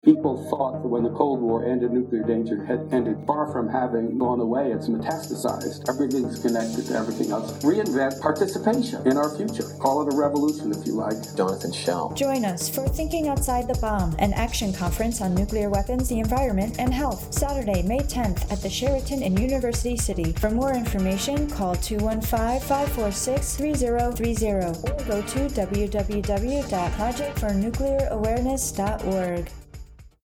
Through voiceovers, originally recorded sound and sampled music, the desired effect is acheived.
30sec_PNA_PSA_ohm.mp3